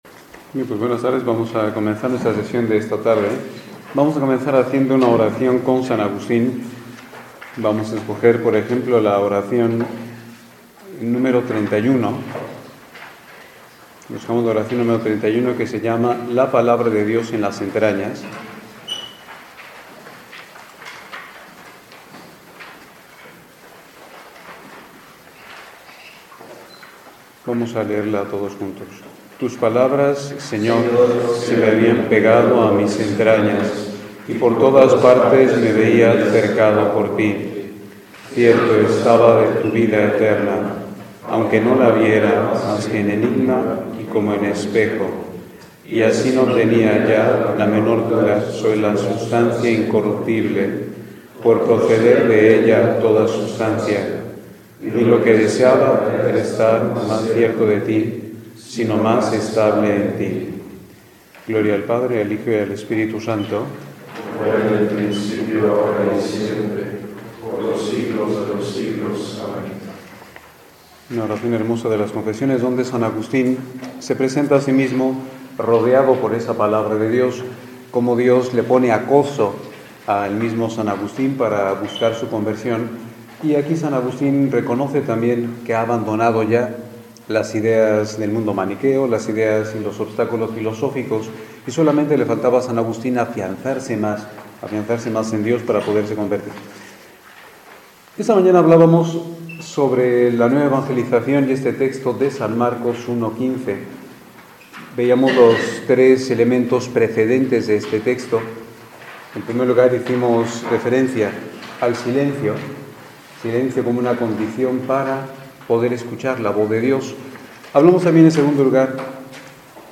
Ejercicios Espirituales